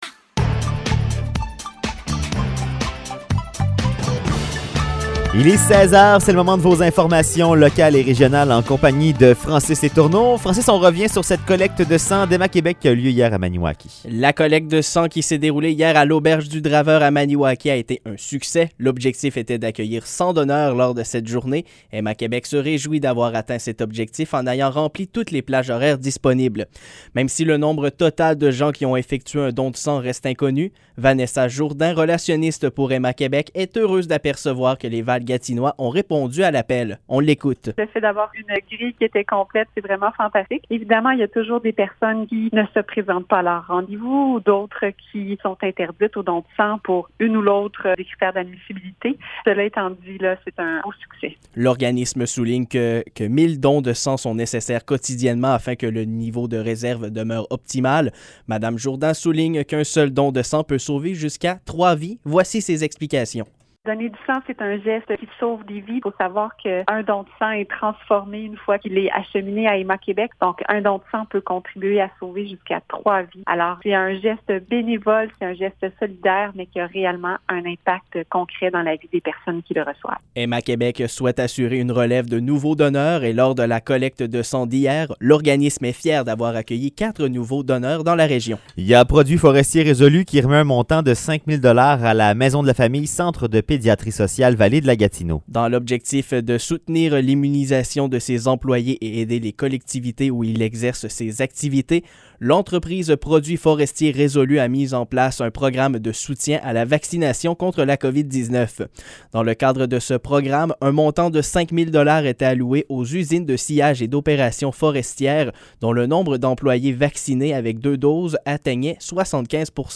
Nouvelles locales - 21 octobre 2021 - 16 h